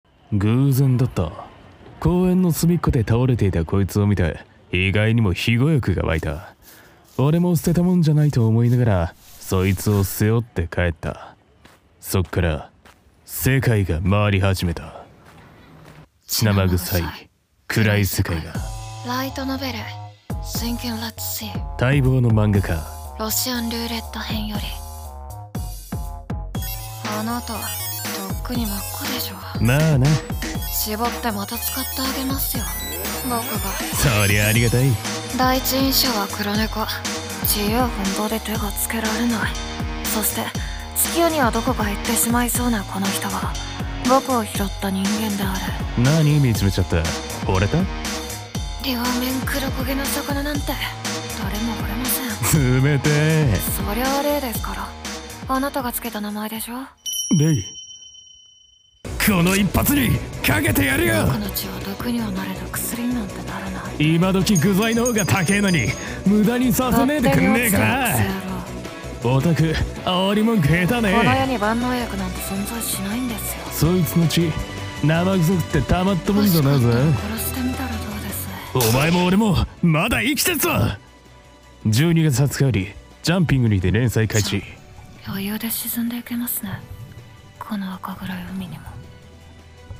CM風声劇「SINK in REDSEA